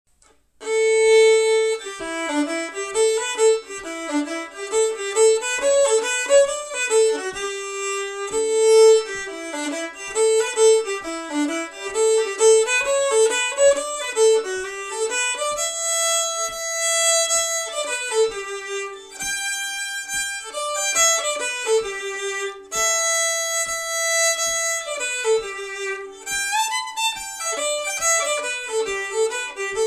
Key: Ador(?)
Form: Reel (Old-time)
Source: Trad.